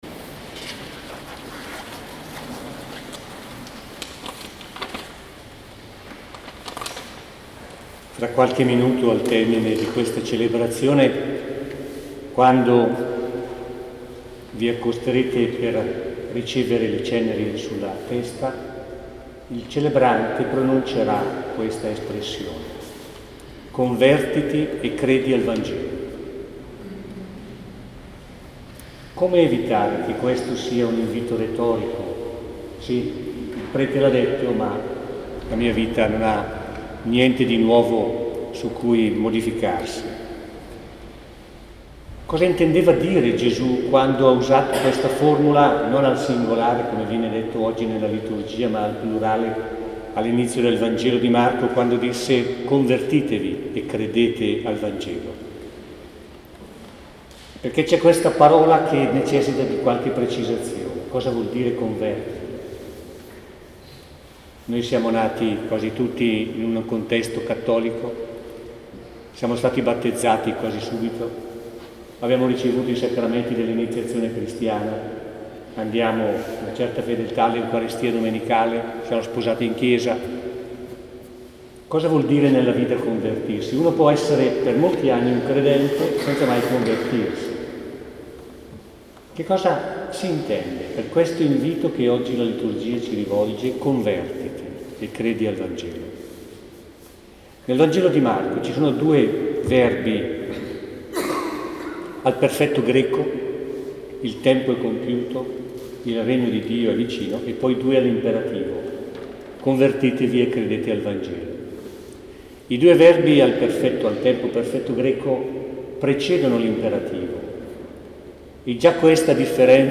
Mercoledì 22 febbraio ci siamo ritrovati presso la Chiesa delle Grazie per vivere insieme il rito delle Ceneri e la liturgia della Parola.